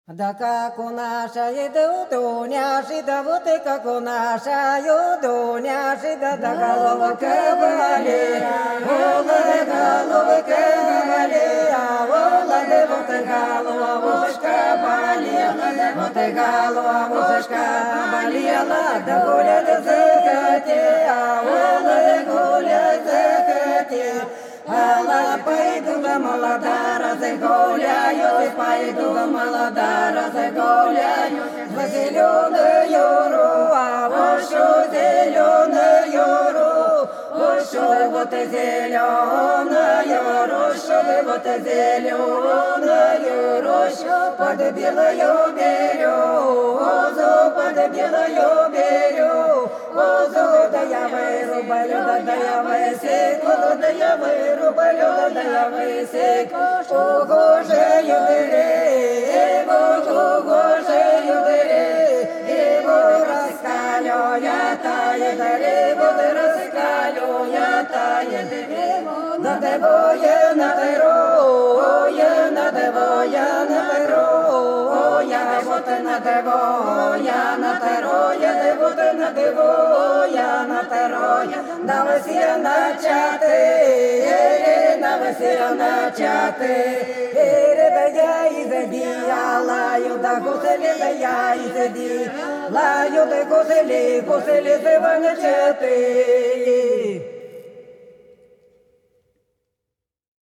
Белгородские поля (Поют народные исполнители села Прудки Красногвардейского района Белгородской области) Как у нашей у Дуняши головка болела - плясовая